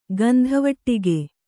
♪ gandhavaṭṭige